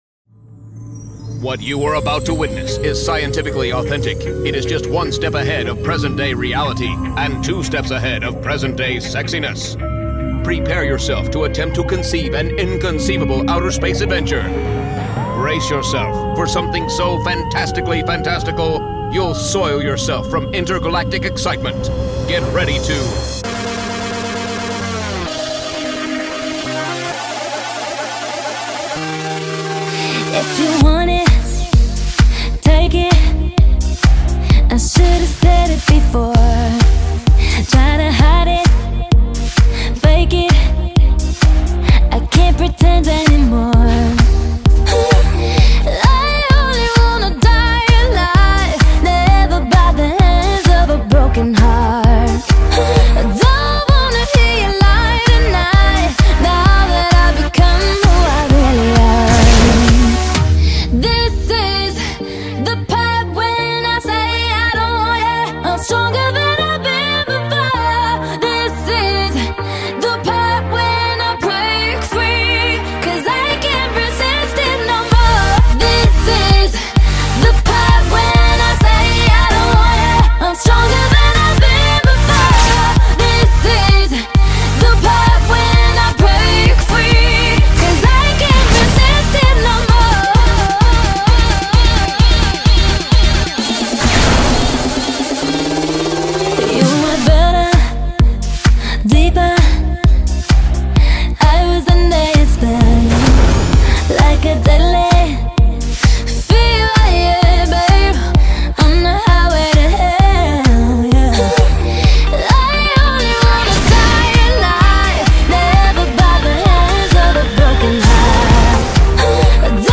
Carpeta: Baile internacional mp3